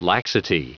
Prononciation du mot laxity en anglais (fichier audio)
Prononciation du mot : laxity